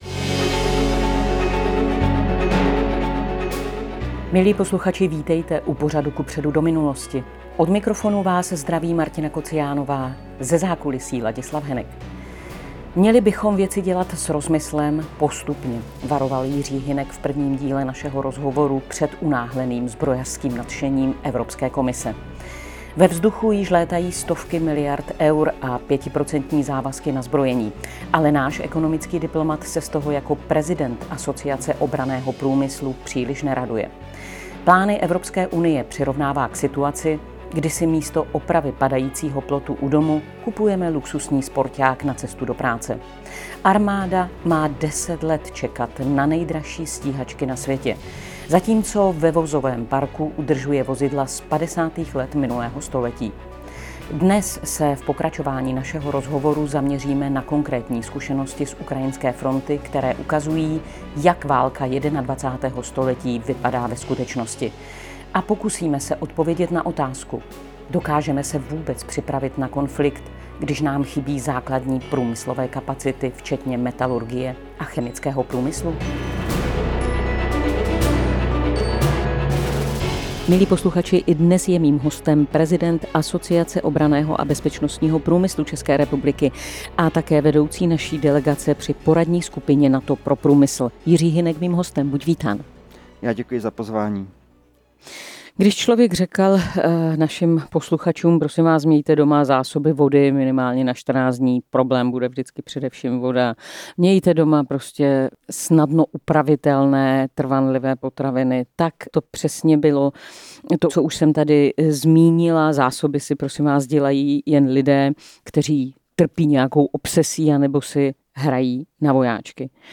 Dnes se v pokračování našeho rozhovoru zaměříme na konkrétní zkušenosti z ukrajinské fronty, které ukazují, jak válka 21. století vypadá ve skutečnosti, a pokusíme se odpovědět na otázku: Dokážeme se vůbec připravit na konflikt, když nám chybí základní průmyslové kapacity, včetně metalurgie a chemického průmyslu?